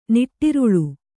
♪ niṭṭiruḷu